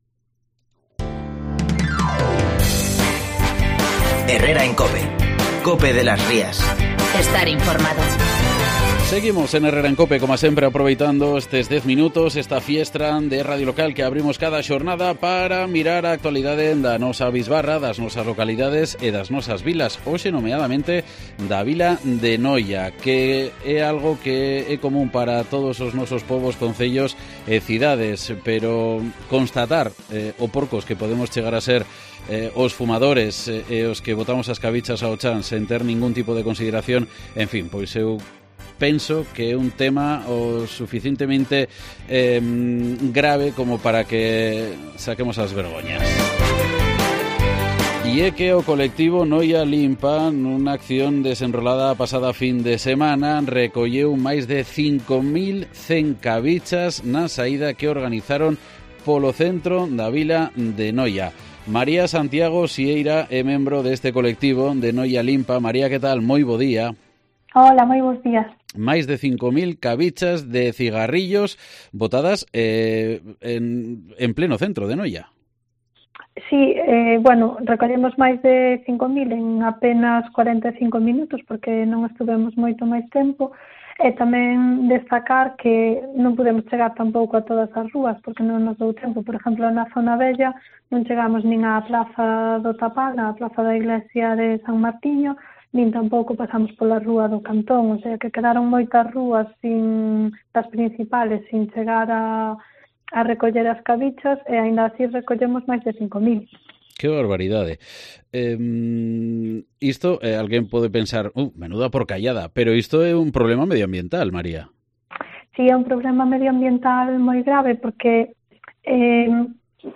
AUDIO: Escucha la entrevista en Cope de las Rías